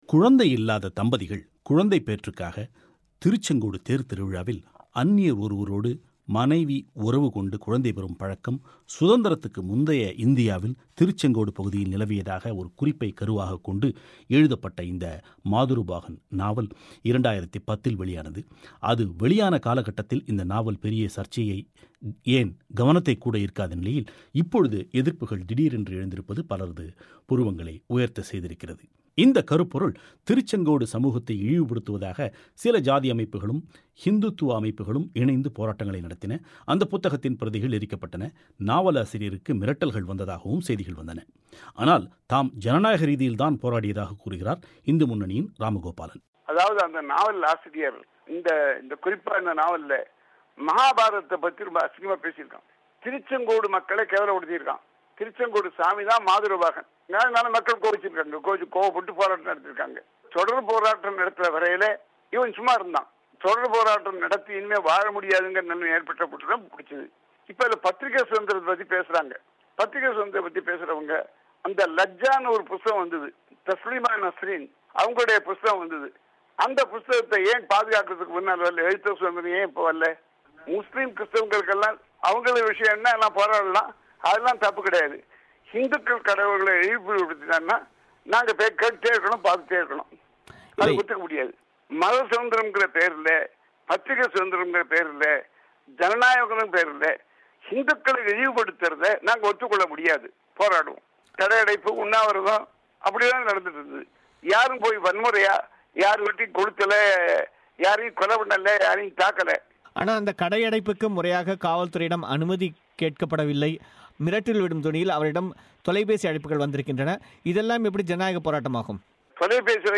தமிழக எழுத்தாளர் பெருமாள்முருகன் எழுதிய மாதொரு பாகன் என்ற புதினம் குறித்து சில இந்துத்துவ அமைப்புகளும், சாதி அமைப்புகளும் எழுப்பிய எதிர்ப்பை அடுத்து, அந்தப்புத்தகத்தைத் திரும்ப்ப் பெறுவதாக திங்கட்கிழமை பெருமாள் முருகன் அறிவித்த்தை அடுத்து, தமிழகத்தில் எழுத்தாளர்களுக்கு கருத்து சுதந்திரம் குறித்த சர்ச்சை பெரிய அளவில் எழுந்திருக்கிறது. இது குறித்த ஒரு பிபிசி தமிழோசை பெட்டகம்